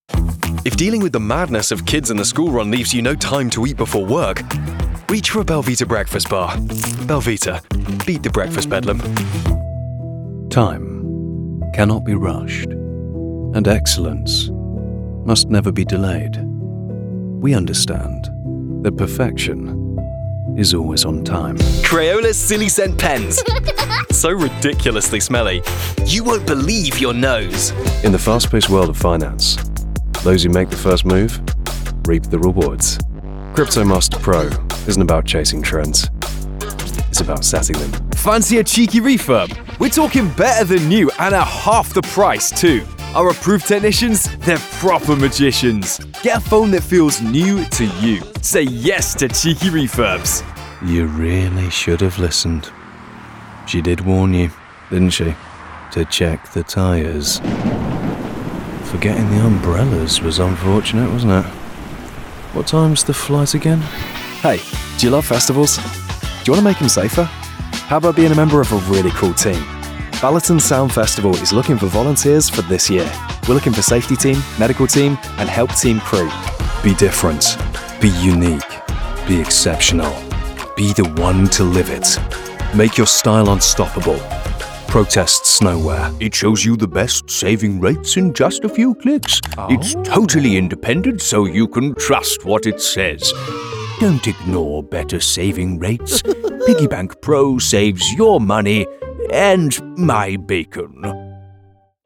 Comercial, Natural, Amable, Cálida, Versátil
Comercial